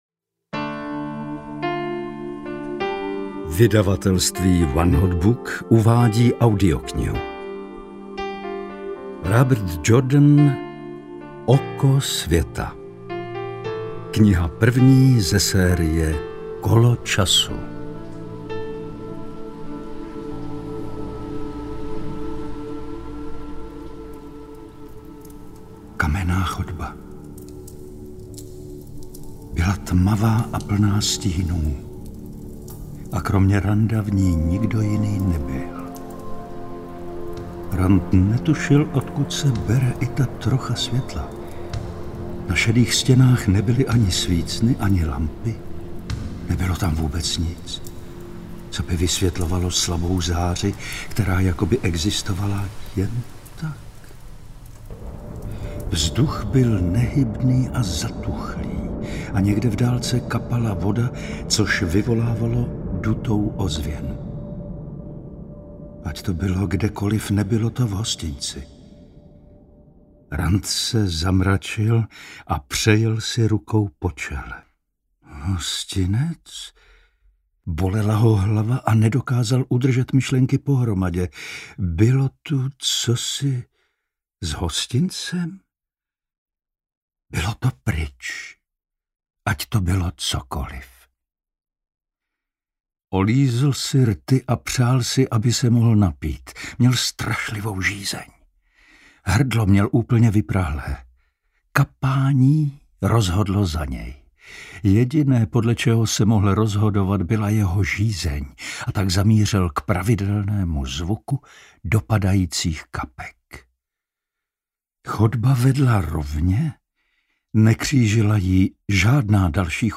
Oko světa audiokniha
Ukázka z knihy